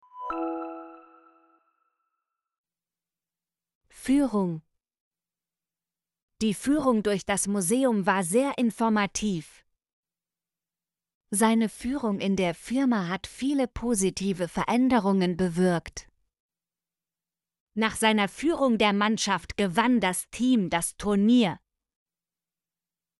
führung - Example Sentences & Pronunciation, German Frequency List